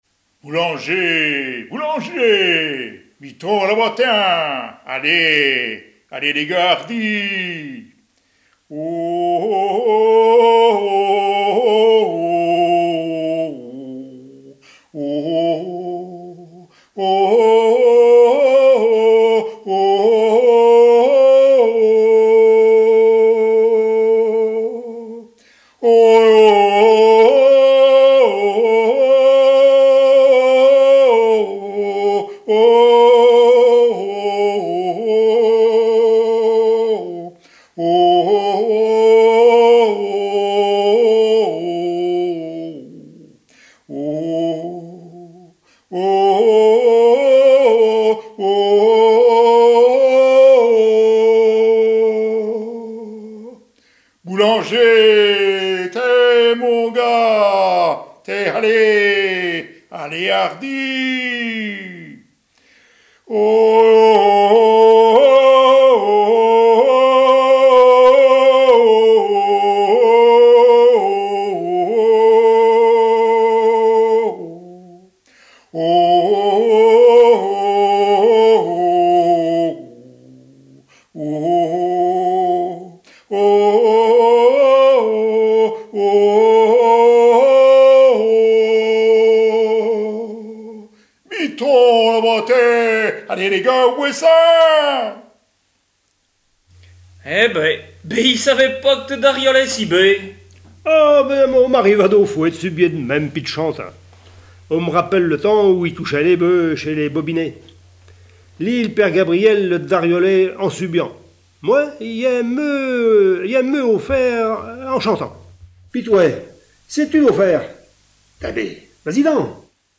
Appels de labour, tiaulements, dariolage, teurlodage, pibolage
couplets vocalisés